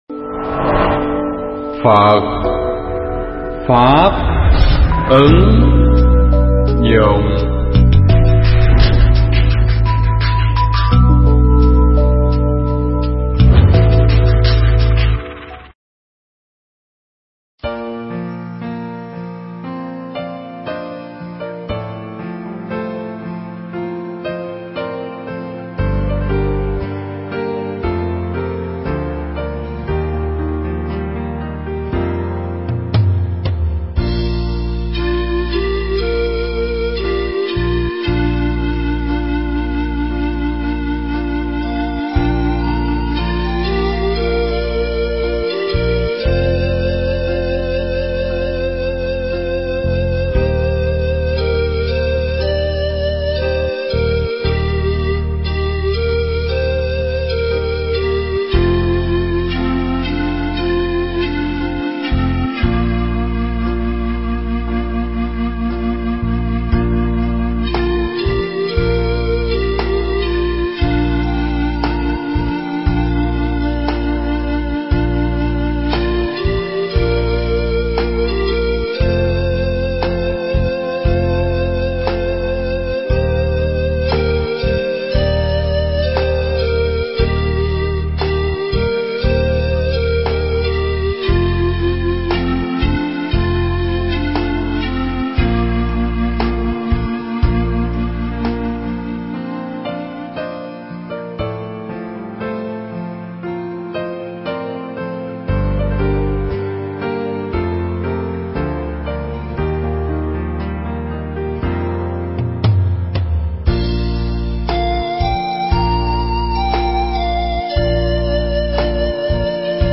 Thuyết pháp Khánh Tuế Đầu Xuân 2014
giảng tại tu viện Tường Vân nhân ngày mùng 1 tết Nguyên Đán 2014